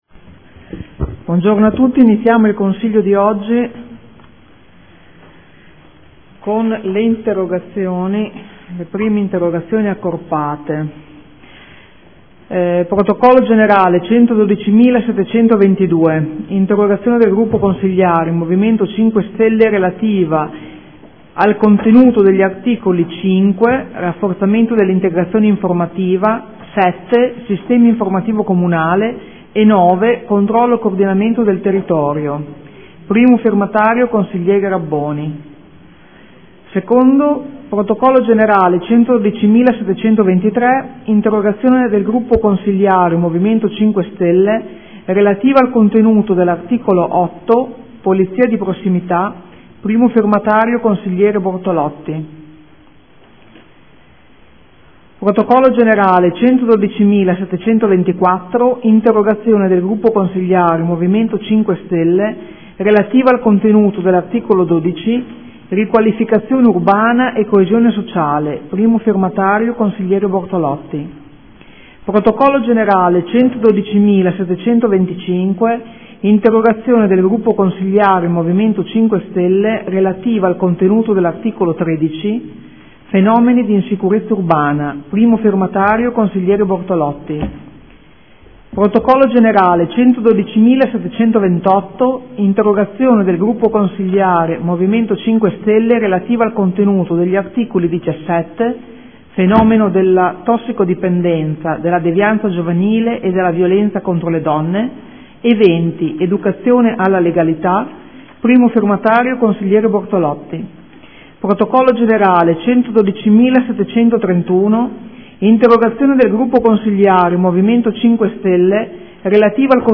Seduta del 9/10/2014 Apre il lavori del Consiglio